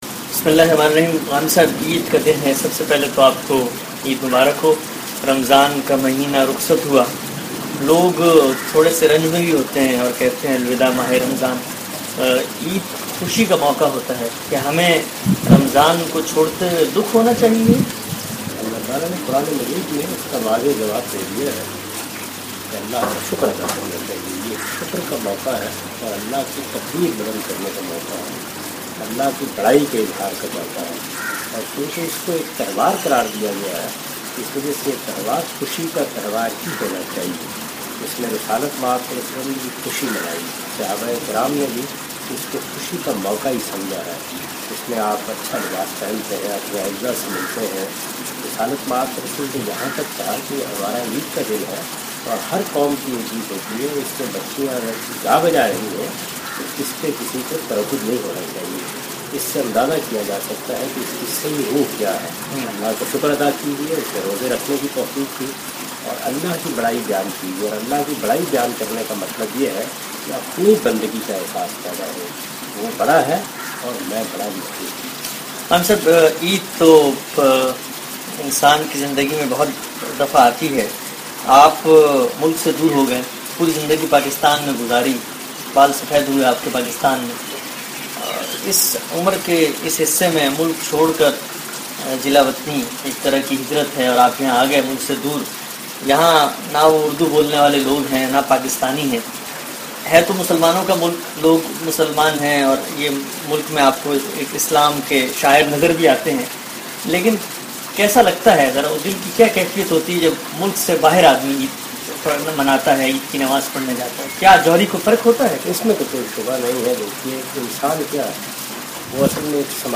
A Short Discussion with Javed Ghamidi Shortly after Eid Prayer